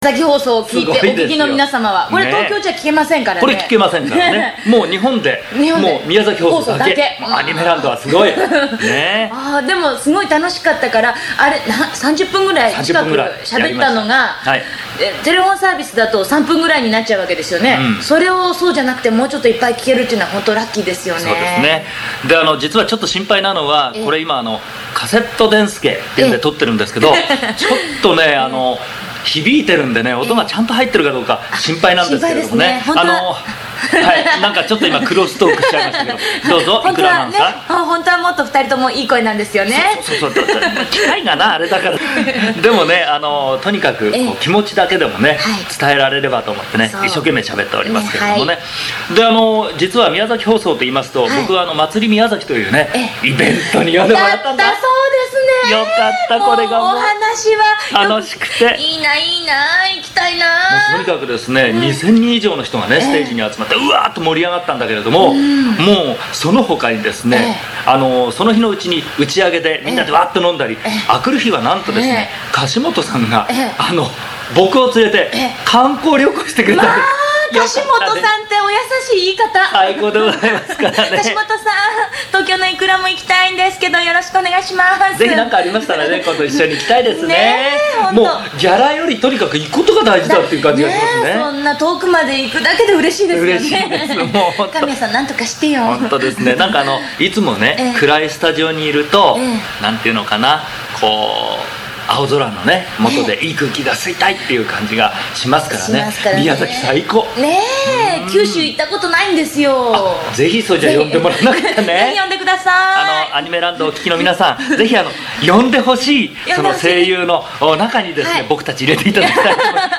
１９８９年１１月、シティハンターの歌の特集をしたが、東京渋谷のシティハンターの録音スタジオから、神谷さん井倉さんがおそろいでカセットに録音して送ってくれた。